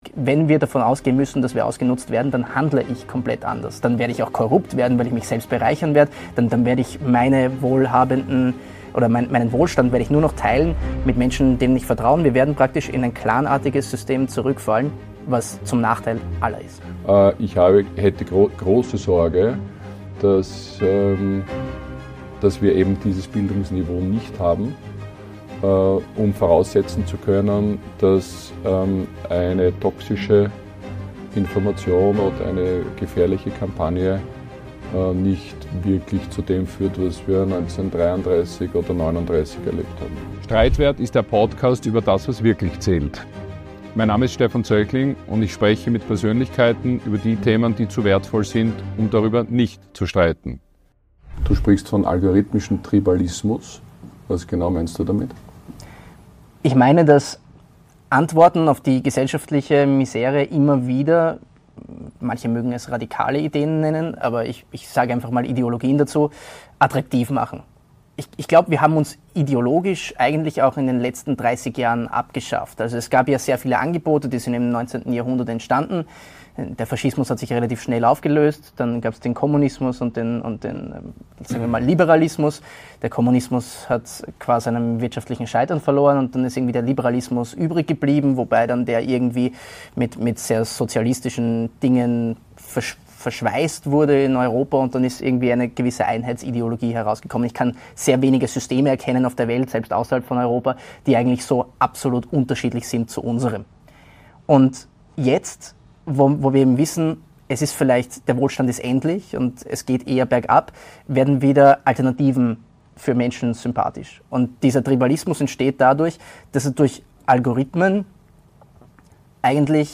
Ein Gespräch über Werte, Generationenbruch, Reform vs. Zerstörung alter Strukturen und darüber, warum die ungewisse Zukunft auch eine Chance für die Jungen sein kann.